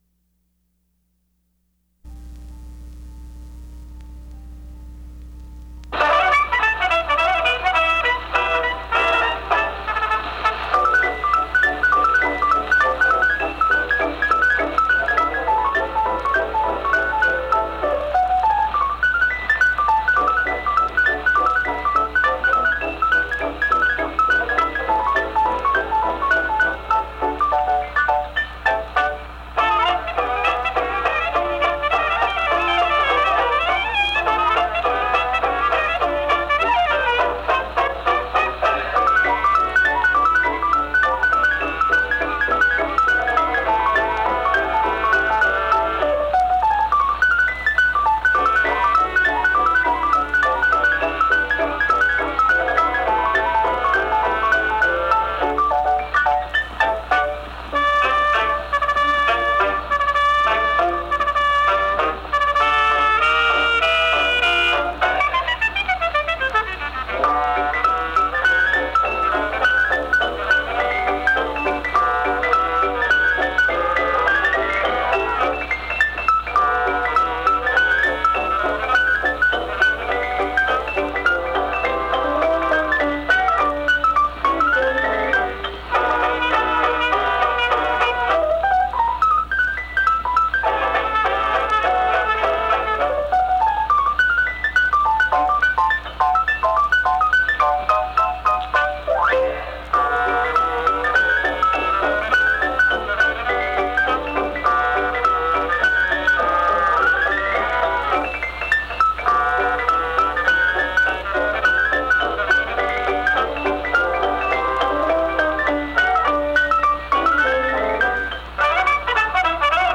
Giuseppe Creatore and His Band performs the song, "Bohemian girl," at the Edison Institute